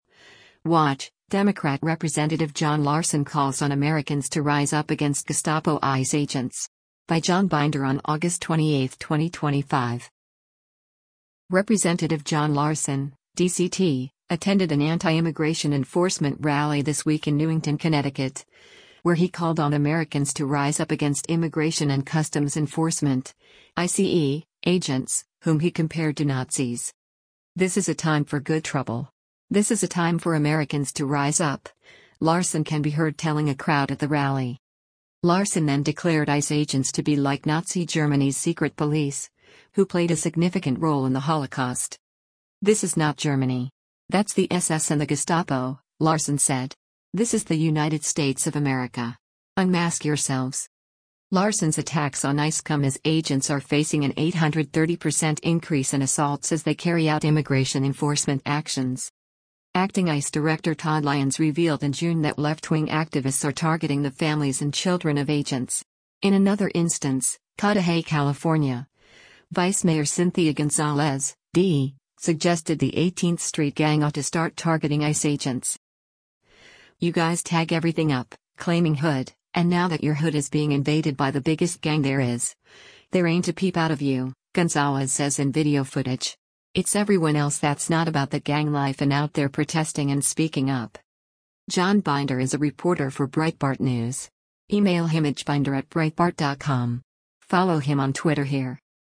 Rep. John Larson (D-CT) attended an anti-immigration enforcement rally this week in Newington, Connecticut, where he called on “Americans to rise up” against Immigration and Customs Enforcement (ICE) agents, whom he compared to Nazis.
“This is a time for good trouble. This is a time for Americans to rise up,” Larson can be heard telling a crowd at the rally.